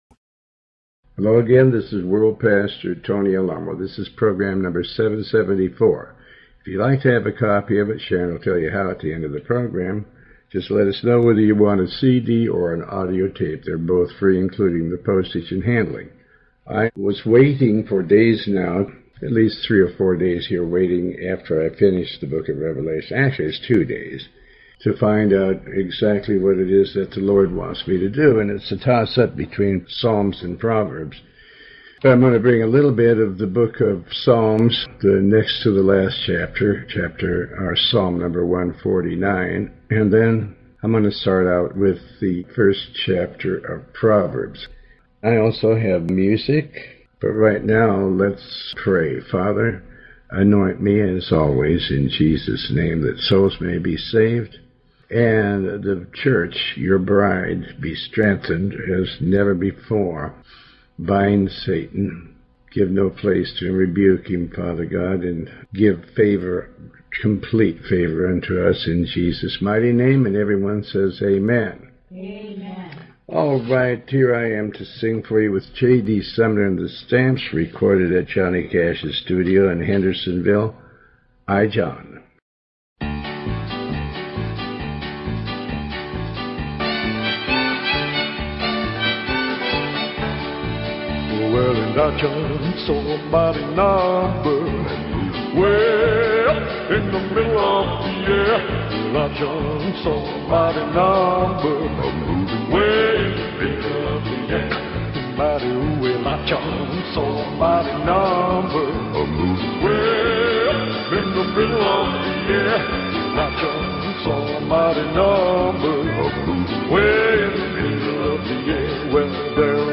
In this program originally recorded in 2008, Pastor Alamo reads from and comments on Psalm 149 and Chapter 1 of the Book of Proverbs up to Chapter 2 verse 7. The program also contains letters and some music.